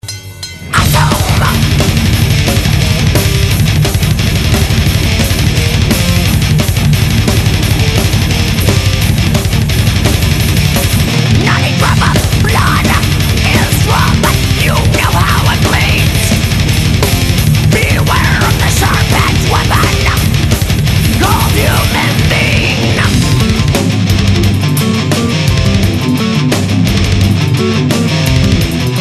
All the samples on this page are 22khz/44khz,16bit,stereo.